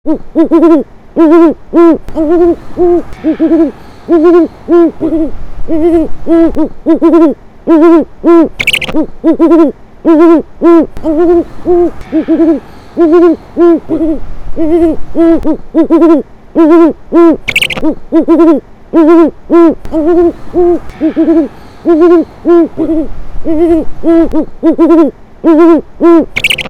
This .wav file is from a female Great Horned Owl at Jungle Island - Miami.
owl_1-2_final.wav